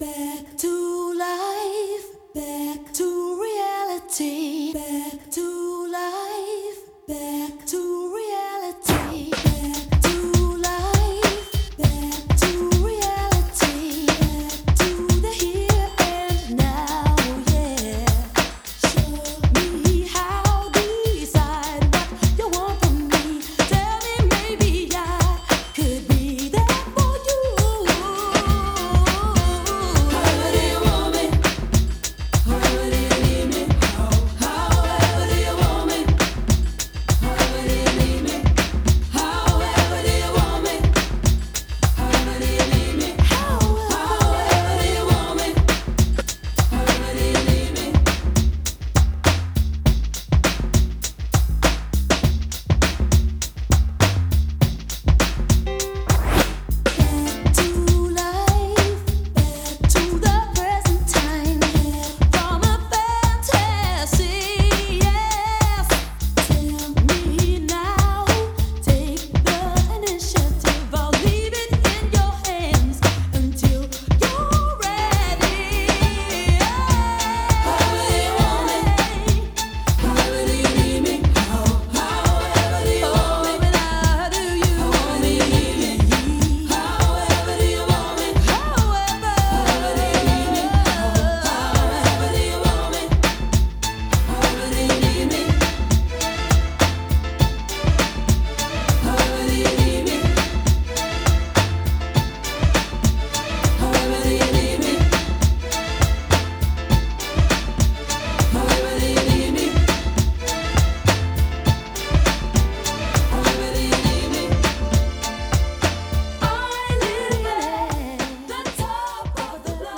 BPM101
Audio QualityLine Out